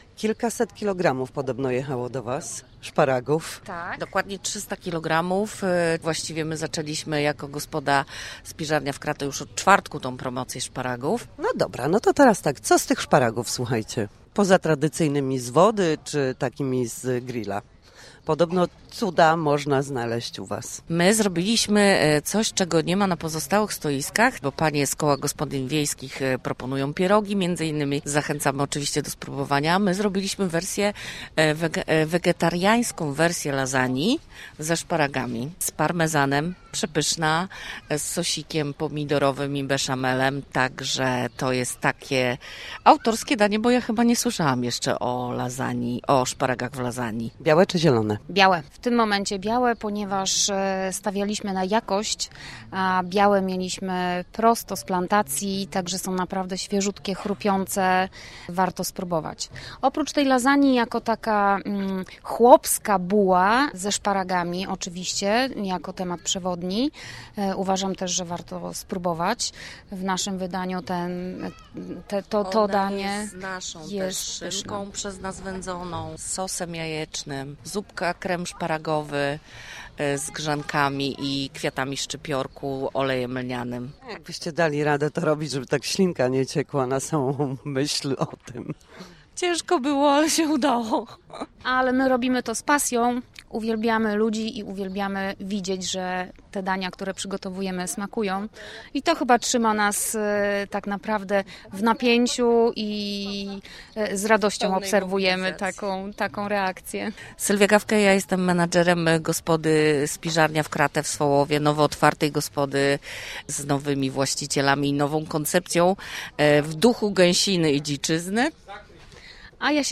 – Czekamy na zupę, ma być za chwilę. Po to przyjechaliśmy, żeby skosztować jak największej liczby dań ze szparagów – mówią odwiedzający Swołowo.
Nie zawsze wychodzi, ale jest to jakaś inspiracja dla nas – dodają mieszkanki Słupska.